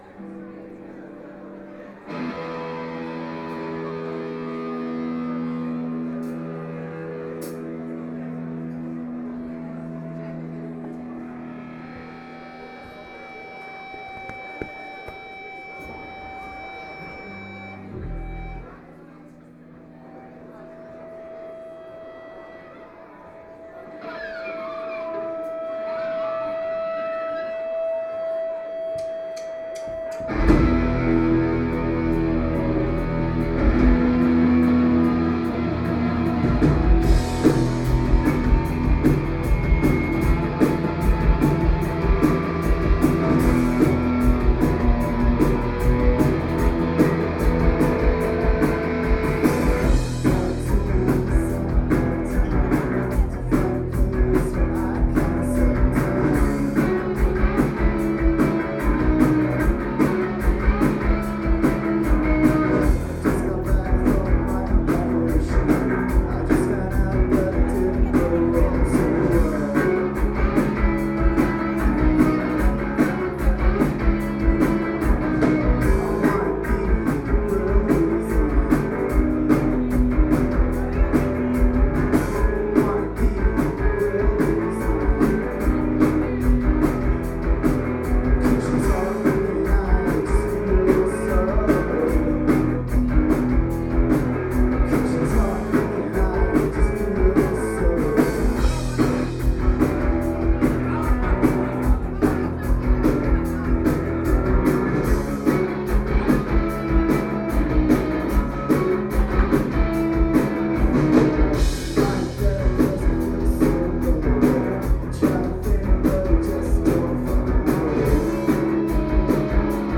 This London quartet